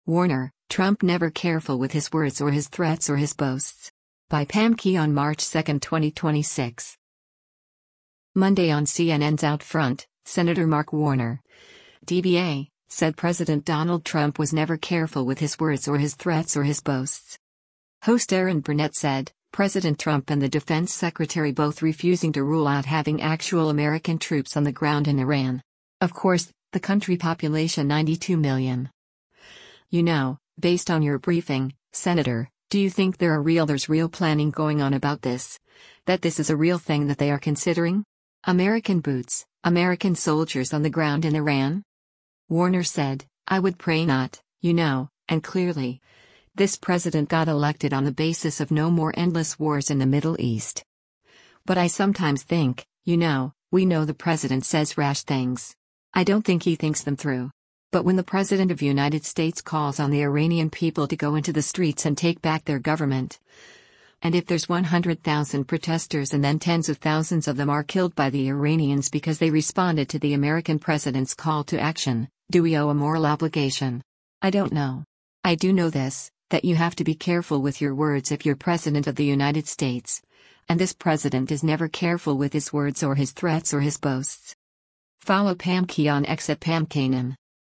Monday on CNN’s “OutFront,” Sen. Mark Warner (D-VA) said President Donald Trump was “never careful with his words or his threats or his boasts.”